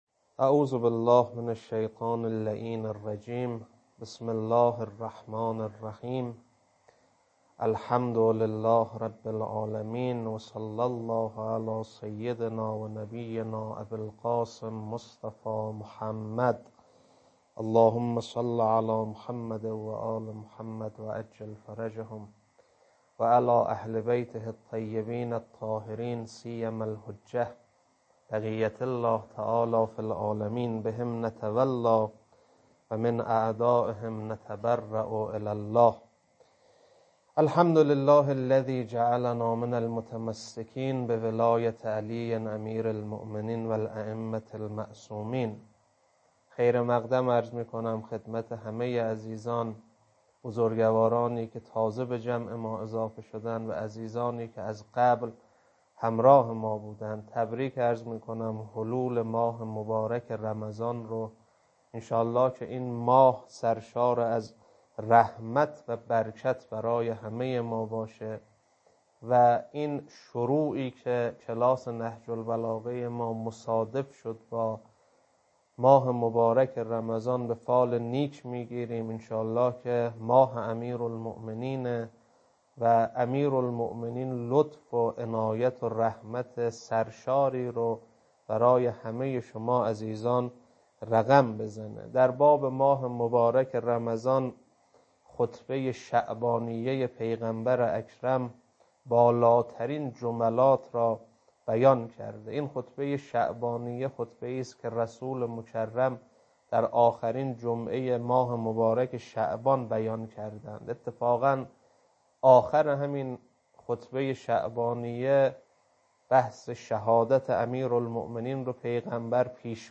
خطبه 1 بخش اول.mp3